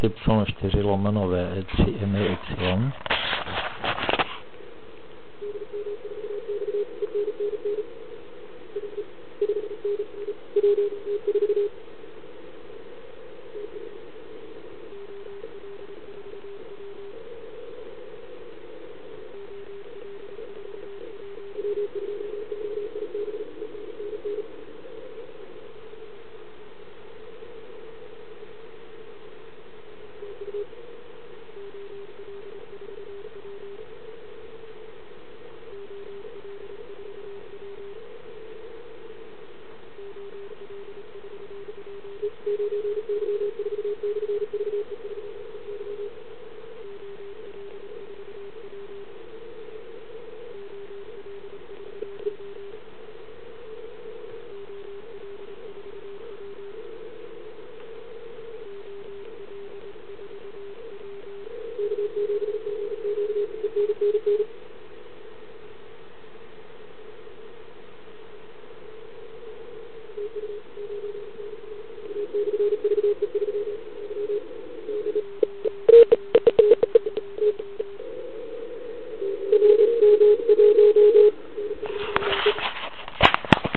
Ale pojďme se zaposlouchat do pár signálků.